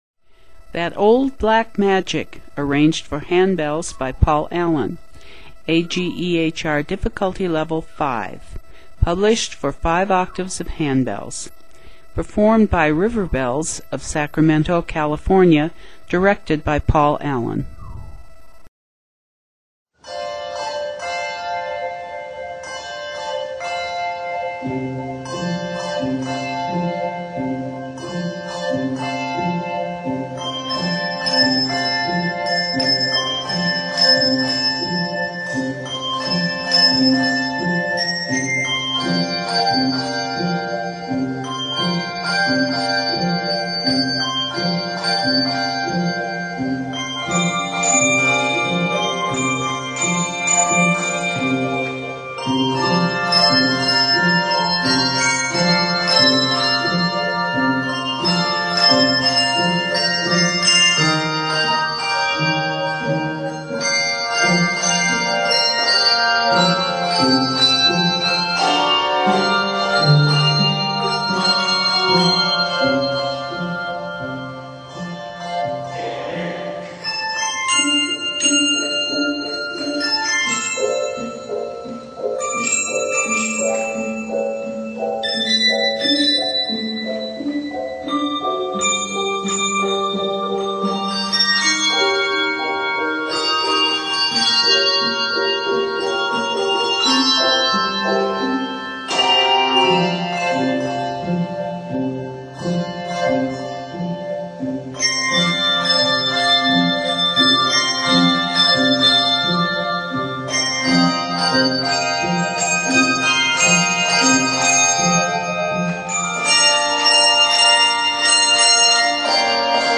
Octaves: 5